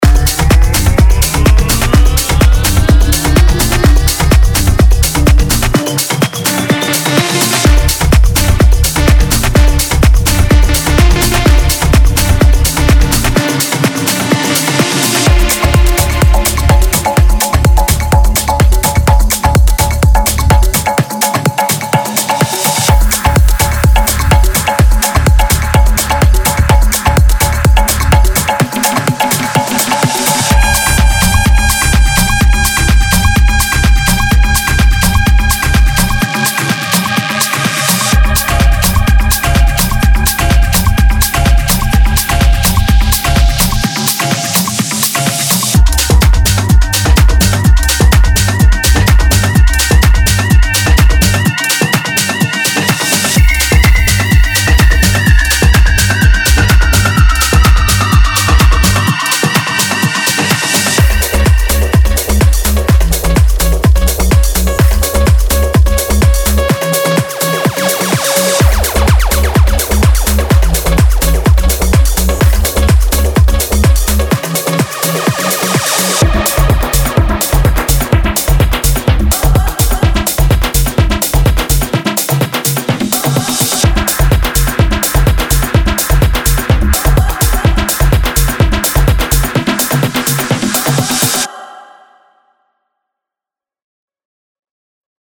House
Demo